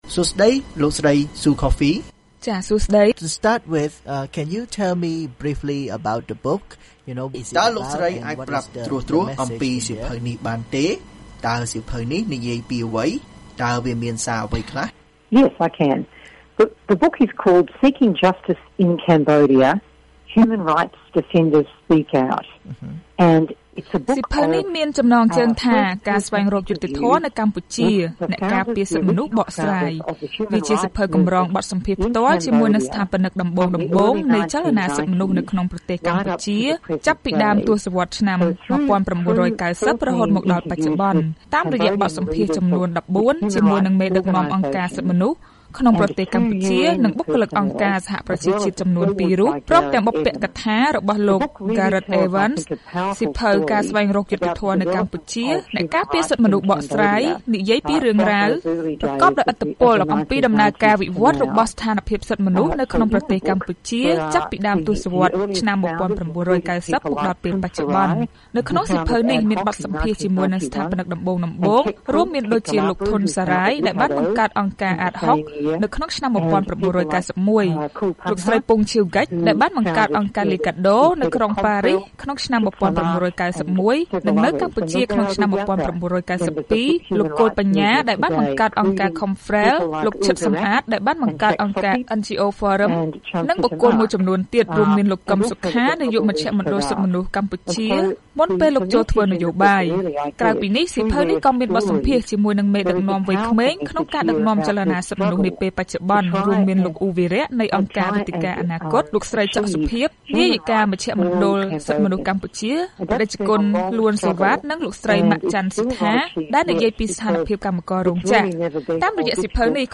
បទសម្ភាសន៍VOA៖ សៀវភៅ«ការស្វែងរកយុត្តិធម៌នៅកម្ពុជា‍»បង្ហាញពីការលះបង់របស់សកម្មជន